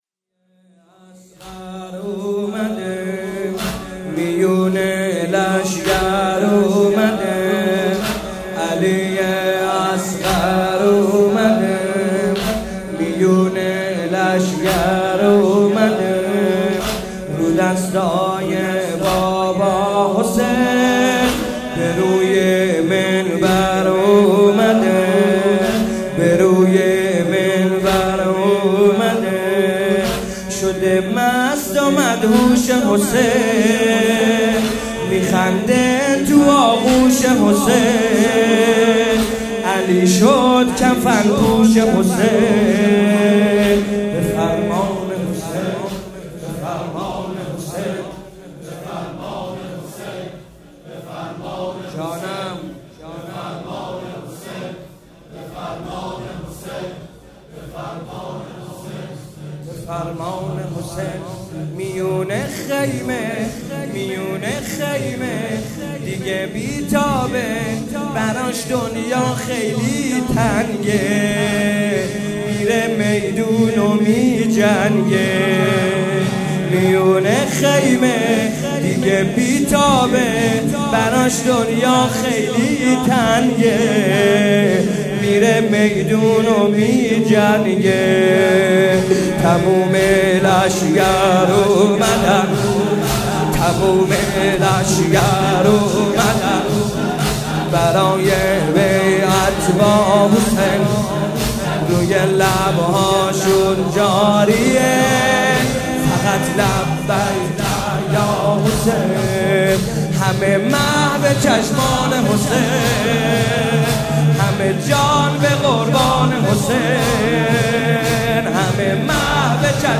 شب هفتم محرم 95 - تک - میون لشکر اومد